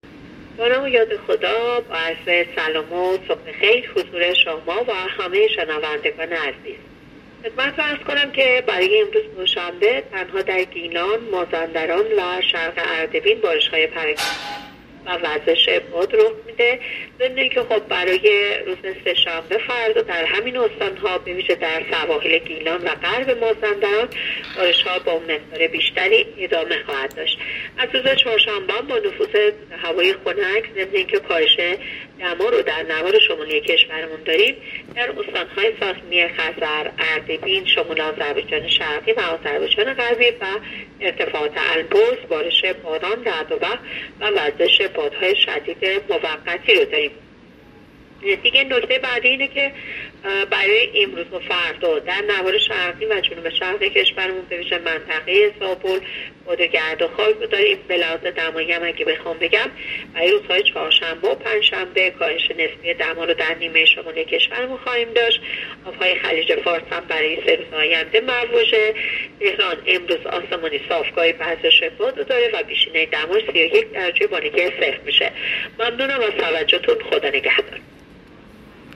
گزارش رادیو اینترنتی پایگاه‌ خبری از آخرین وضعیت آب‌وهوای هفتم مهر؛